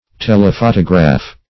Telephotograph \Tel`e*pho"to*graph\, n. [Gr. th^le far +